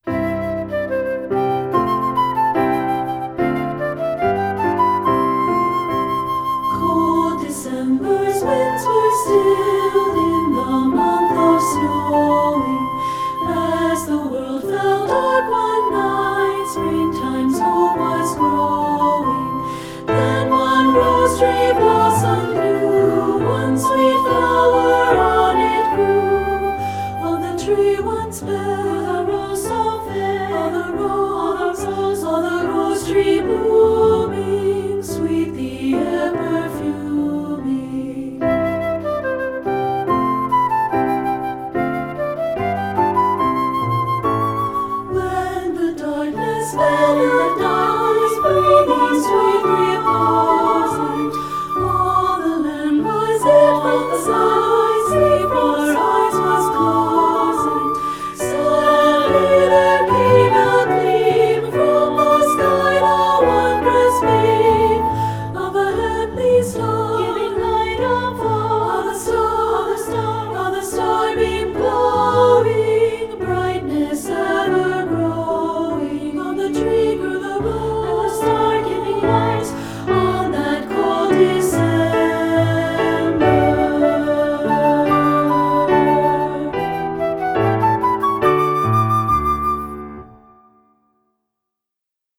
Voicing: 2-Part, Piano and Flute